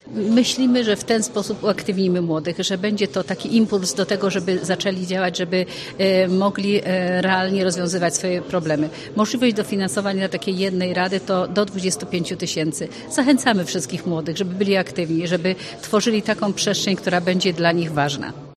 O planach działań mówi Elżbieta Lanc, członkini zarządu województwa mazowieckiego: